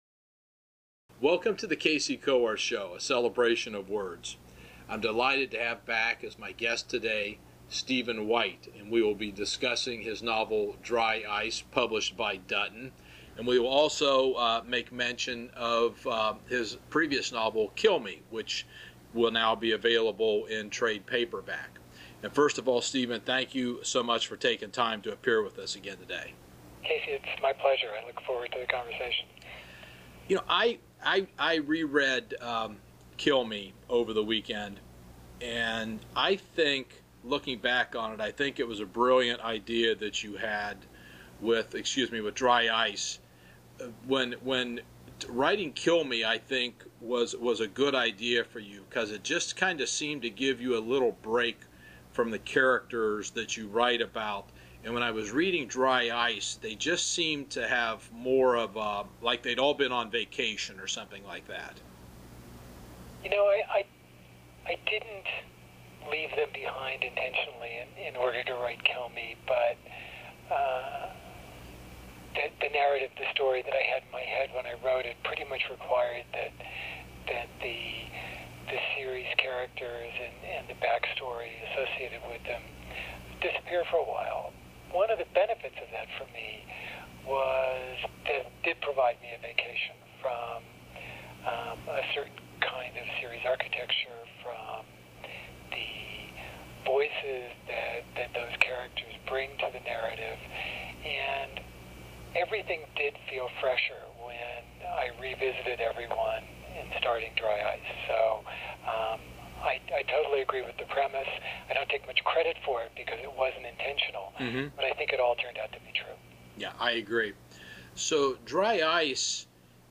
Interviews Stephen White on his novel DRY ICE